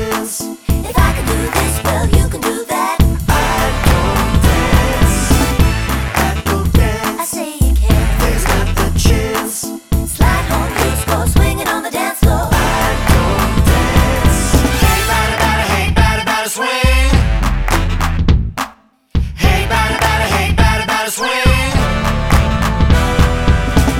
No Backing Vocals Soundtracks 3:35 Buy £1.50